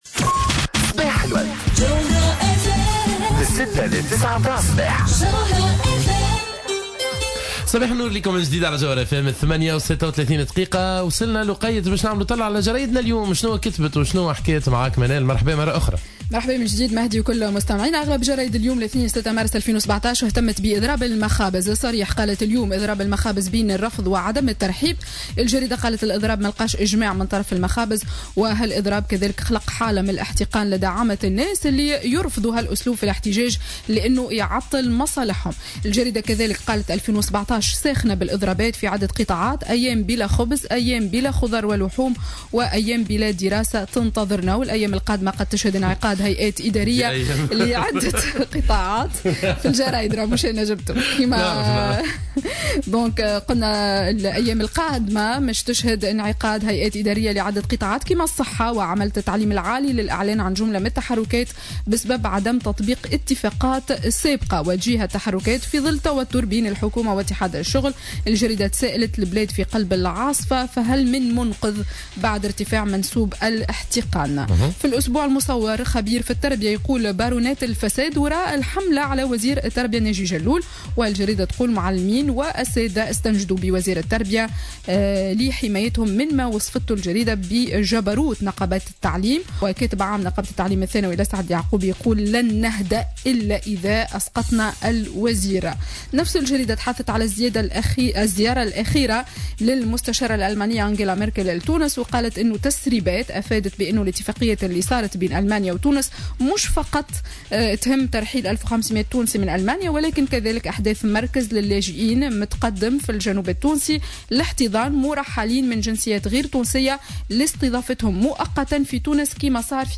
Revue de presse du lundi 06 Mars 2017